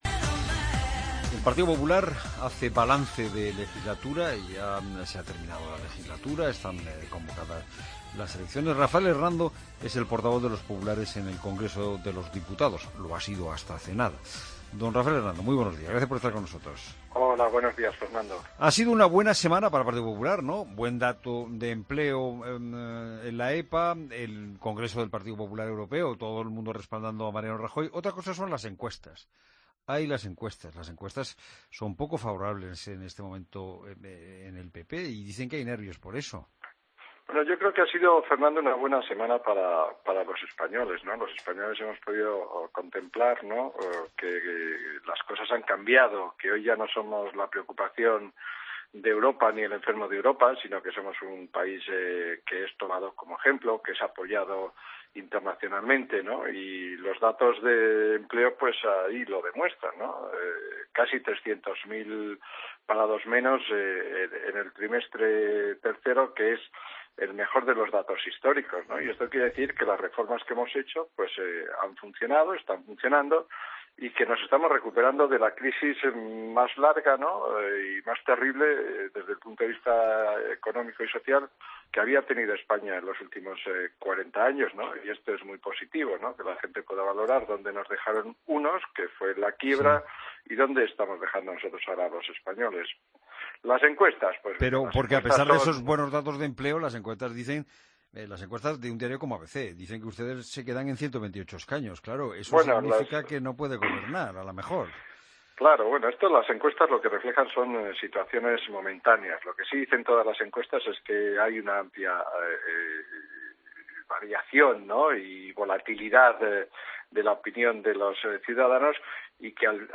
Rafael Hernando, portavoz en el Congreso del PP, en La Mañana de Fin de Semana